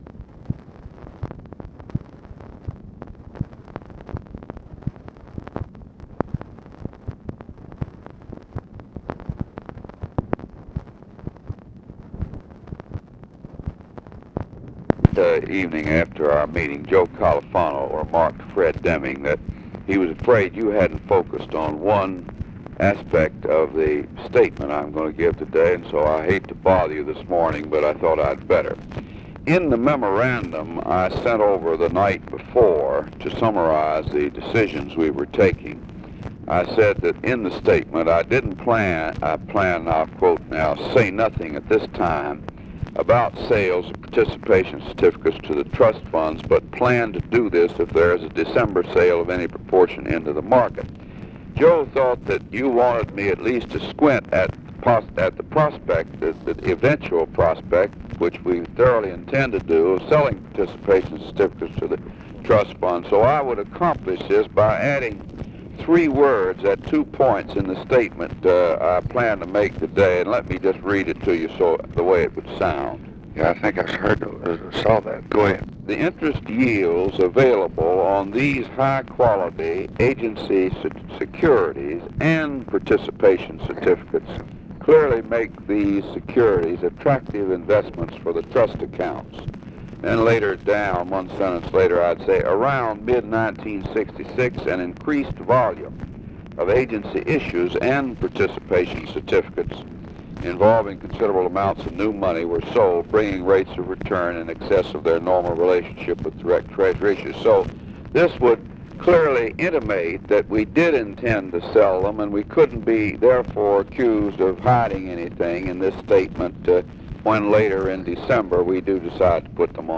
Conversation with HENRY FOWLER, September 10, 1966
Secret White House Tapes